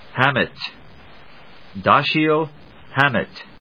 音節Ham・mett 発音記号・読み方
/hˈæmɪt(米国英語)/